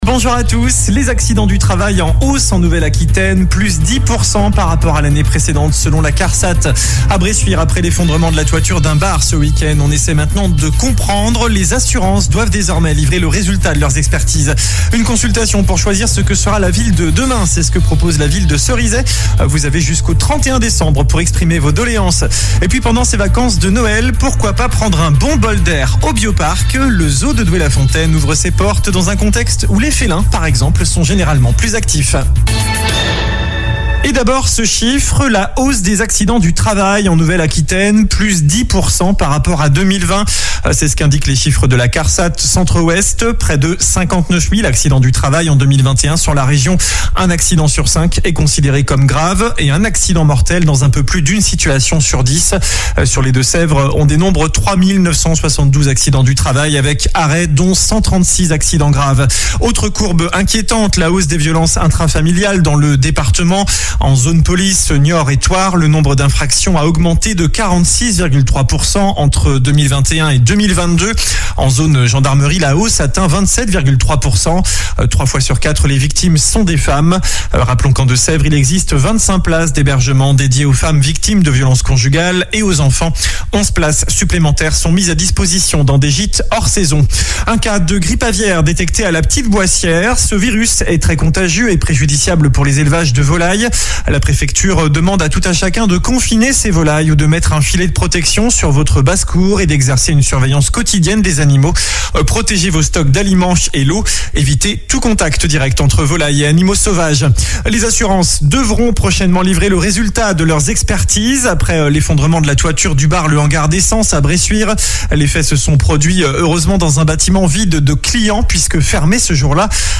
COLLINES LA RADIO : Réécoutez les flash infos et les différentes chroniques de votre radio⬦
Journal du mardi 27 décembre